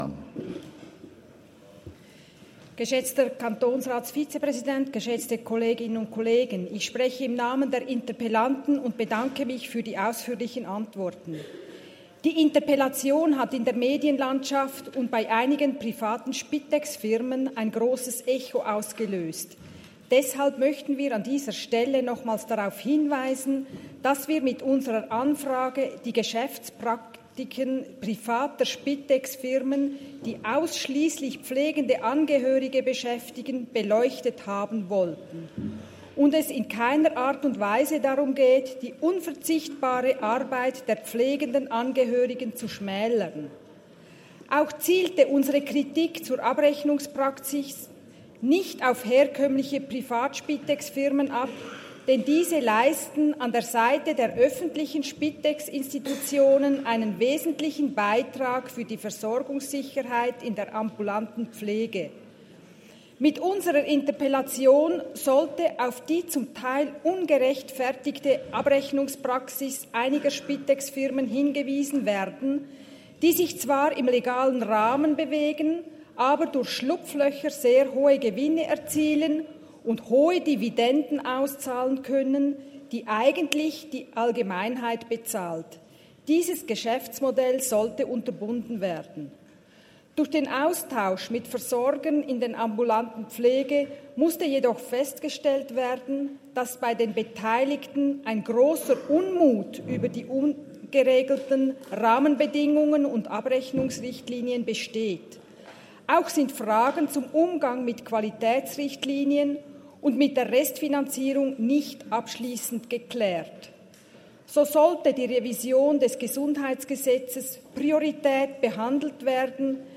Session des Kantonsrates vom 16. bis 18. September 2024, Herbstsession